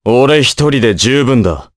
Crow-Vox_Victory_jp.wav